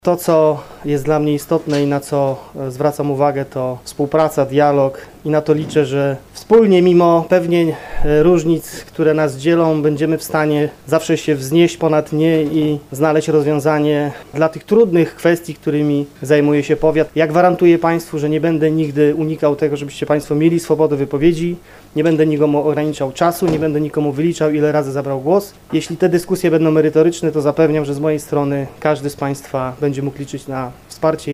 W trakcie dzisiejszej sesji Rady Powiatu Stargardzkiego, wybrano przewodniczącego.
Po tajnym głosowaniu, przewodniczącym Rady Powiatu Stargardzkiego został wybrany Zdzisław Rygiel, który tuż po wyborze powiedział: